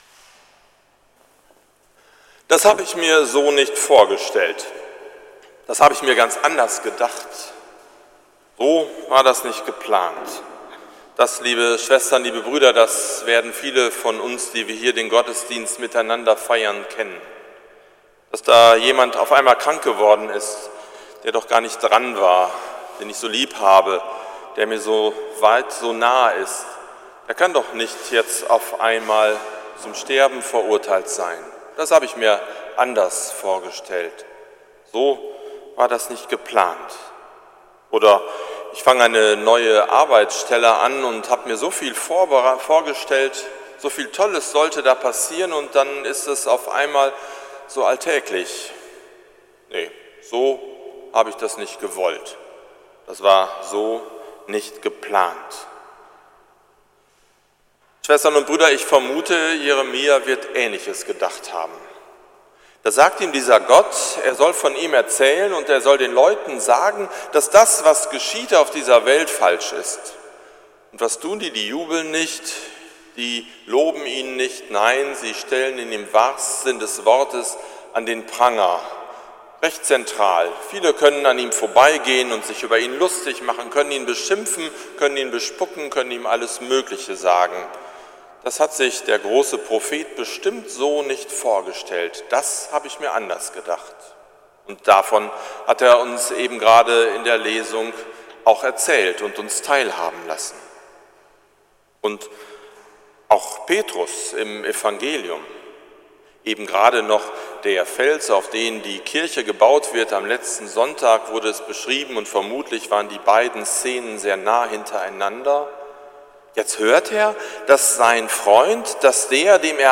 Predigt zum 22. Sonntag im Jahreskreis 2017 – St. Nikolaus Münster